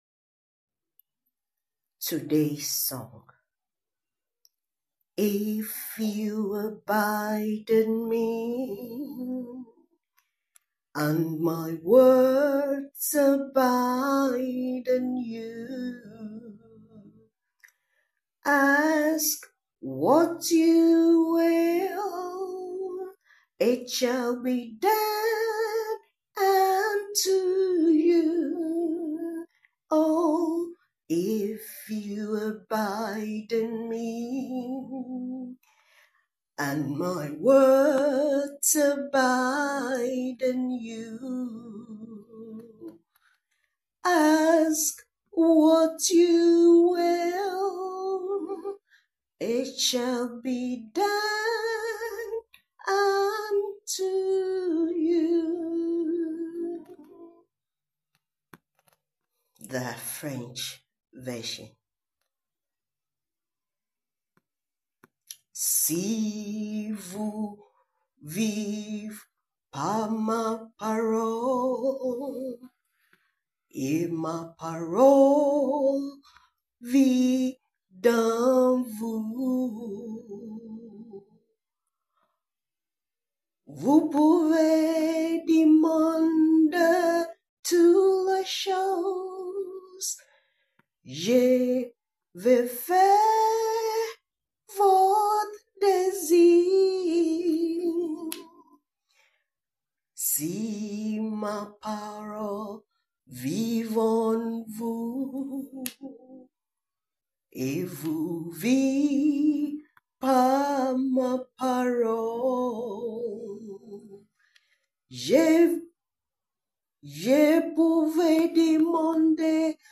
Song for meditation